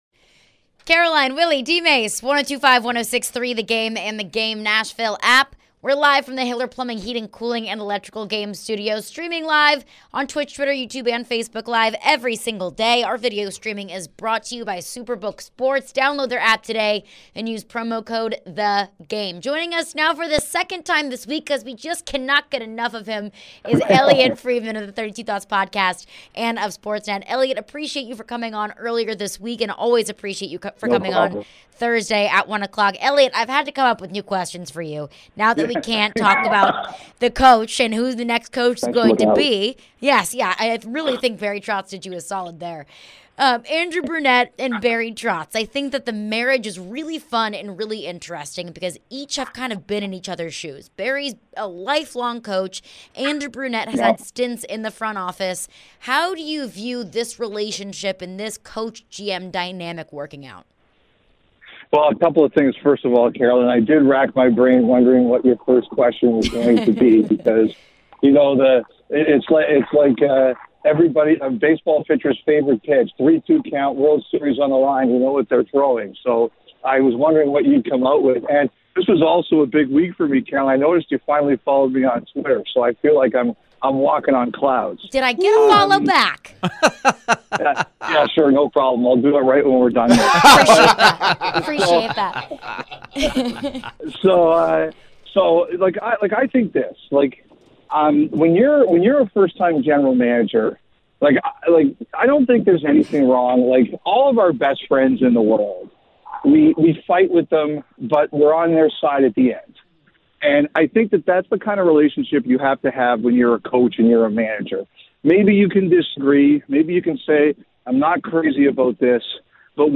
Elliotte Friedman Interview (6-1-23)